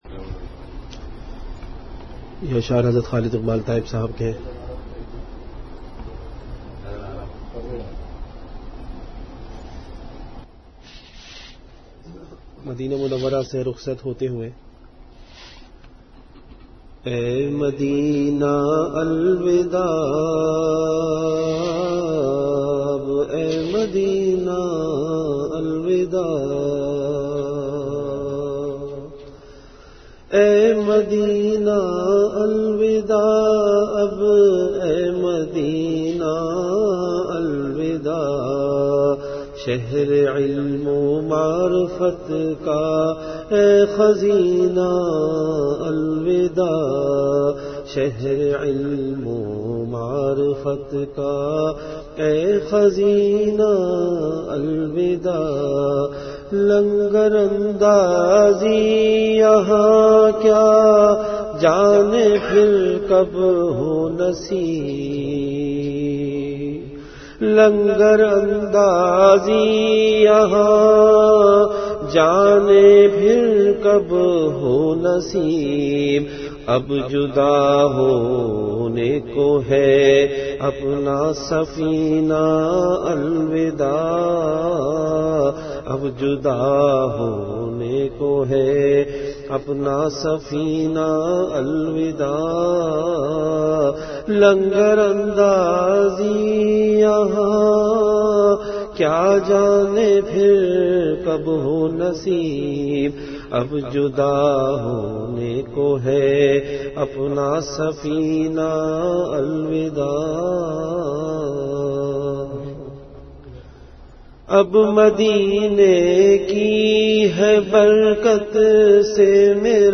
Delivered at Madinah Munawwarah.